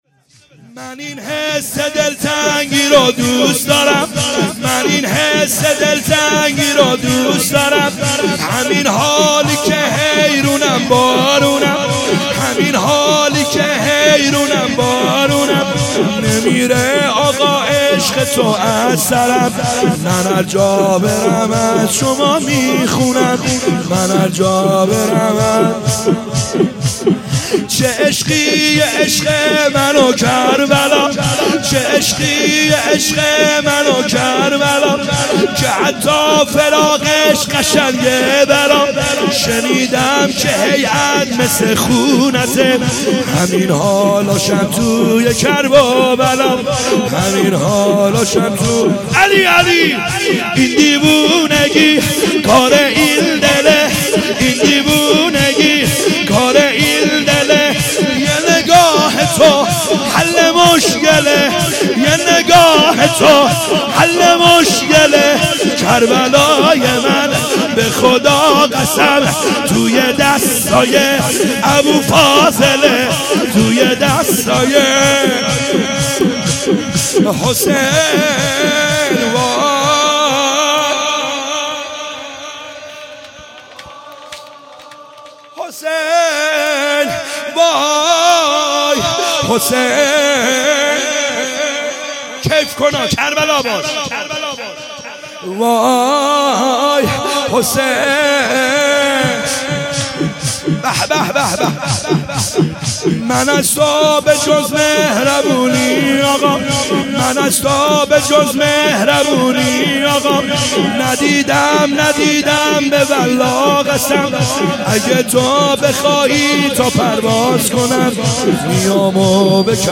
خیمه گاه - بیرق معظم محبین حضرت صاحب الزمان(عج) - شور | من این حس دلتنگی رو